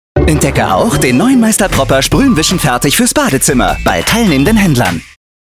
sehr variabel
Mittel minus (25-45)